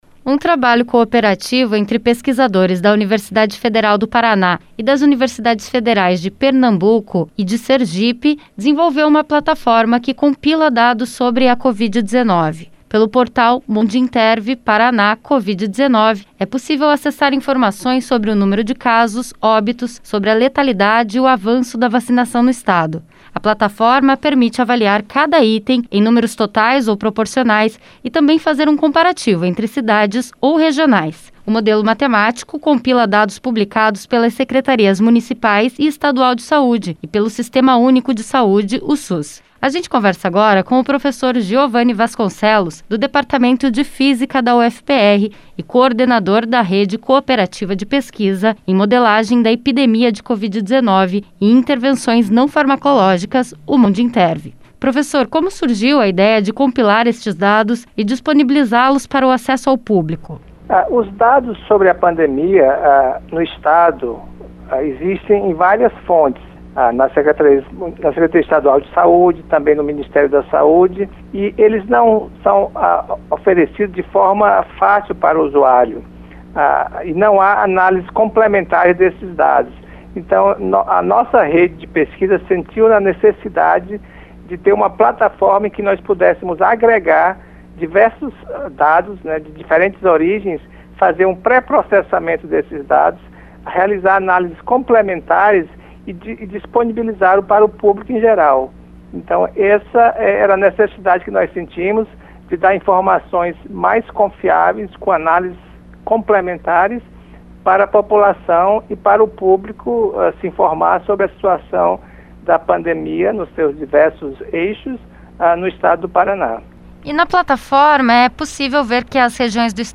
ENTREVISTA: No Paraná, regionais de Foz do Iguaçu e Paranaguá são as mais afetadas pela pandemia